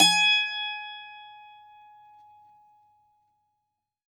STRINGED INSTRUMENTS
52-str15-bouz-g#4.wav